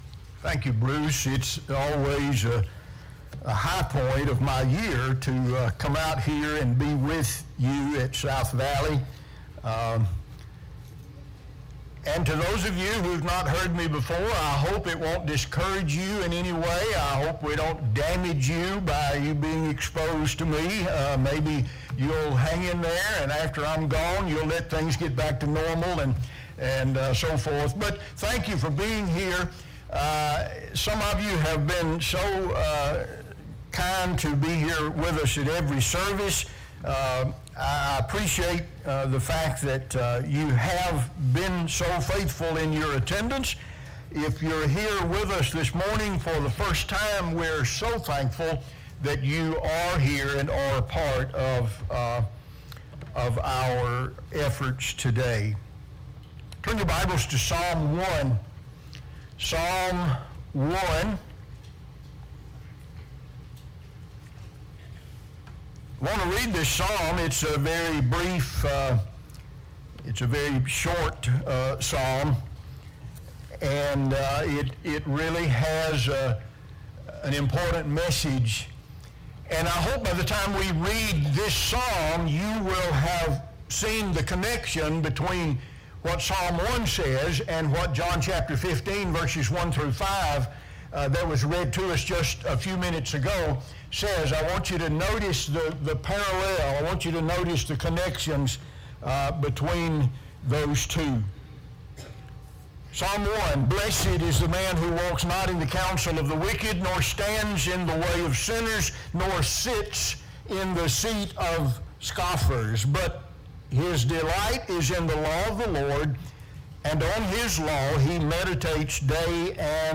2024 (Gospel Meeting - AM Worship) "Rotten Fruit"